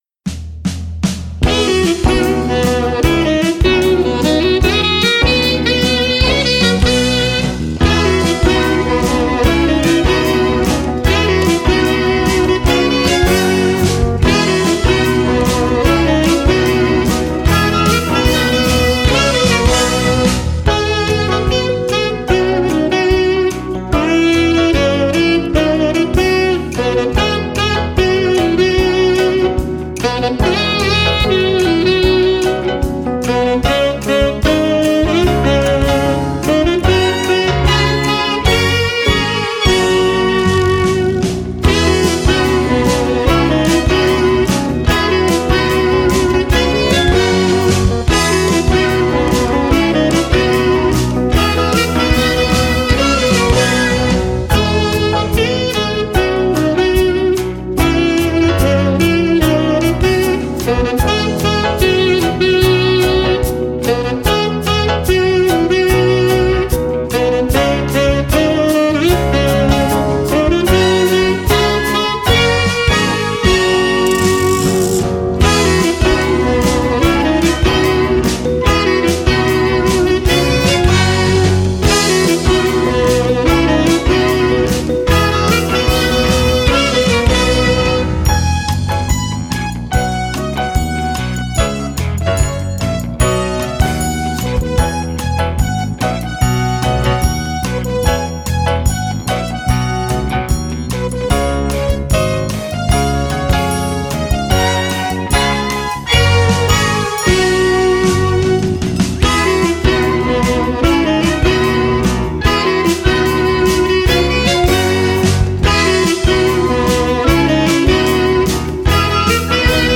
Ich habe hier für euch noch eine Playbackfassung. Damit ihr den Einstieg ins Playback selber gut finden könnt, singe ich euch das zusammen mit dem Playback noch einmal vor.